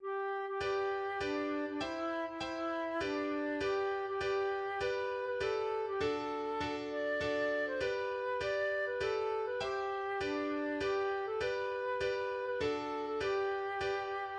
\header{ dedication = "🏰008" title = "Bin ein Räuber" subtitle = "Trauriges Trinklied" subsubtitle = "I'm a Rover, Seldom Sober" meter = "Volker S." arranger = "traditional" } myMusic = { << \transpose f g { \chords { \germanChords \set chordChanges=##t s4 f4 f4 bes4 bes4 f4 f4 f4 f4 f4 c4 c4 c4 f4 f4 f4 bes4 f4 f4 f4 f4 c4 f4 f4 } } \transpose f g { \relative c' { \time 2/4 \partial 4 \tempo 4=100 \key f \major \set Staff.midiInstrument="Flute" f8. f16 f4 c8. c16 d8. d16 d4 c4 f8. f16 f4 a8. a16 g8. f16 g4~g16 r16 c8 c8. bes16 a4 c8. a16 g8. a16 f4 c4 f8. g16 a4 a8. a16 g8. g16 f4~f4 \fine } } \addlyrics { \set stanza = "Refrain: " Bin ein Räu- ber und auch ein Streu- ner, bin ein Söld- ner, ein ech- ter Dieb.